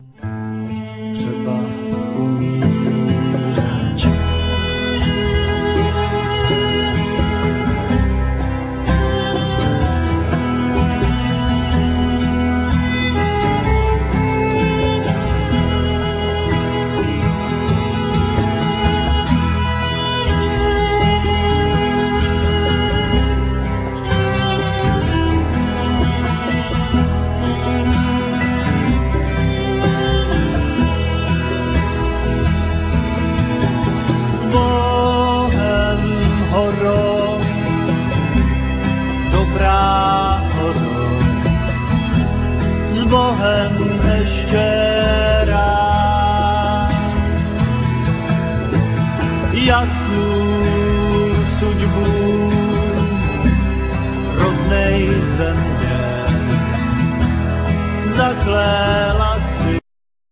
Cena,Flute,Zither
Vocals,Mandolin,Grumle
Violin,Viola
Percussions,Zither,Cymbal
Double-bass
Clarinet
Bell,Percussions